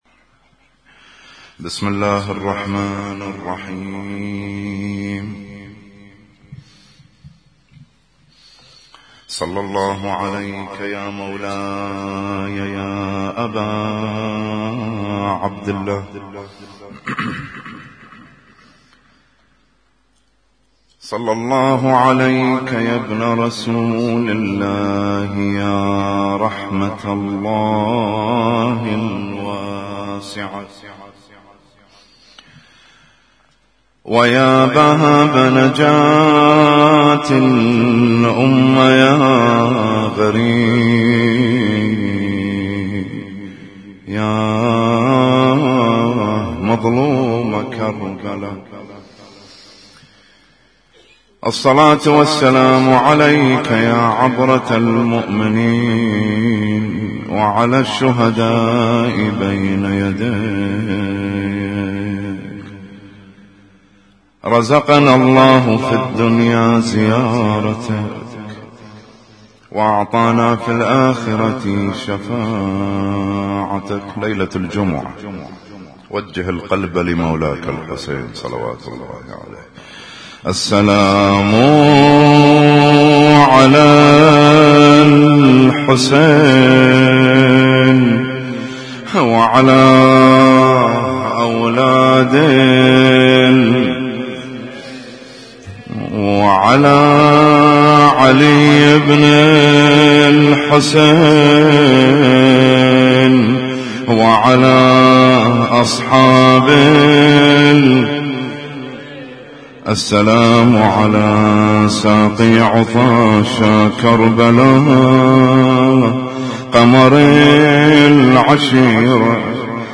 حسينية النور - من البث المباشر